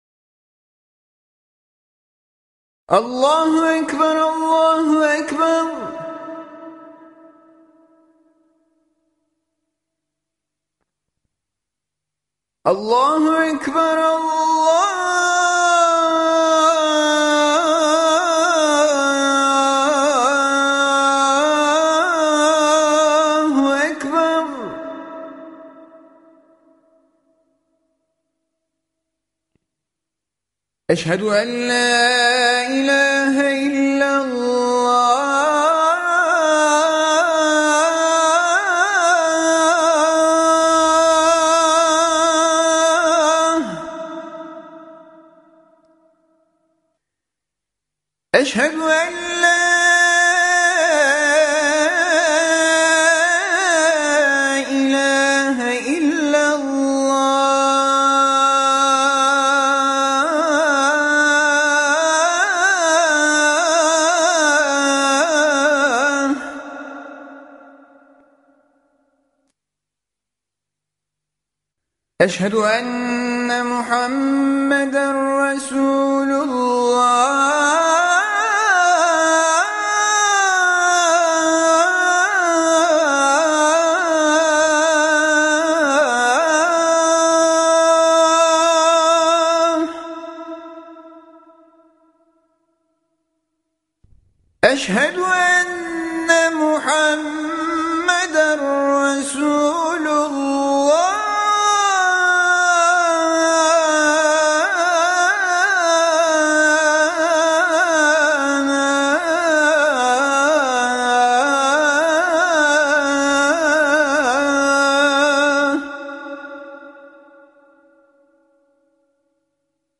athanfajr.mp3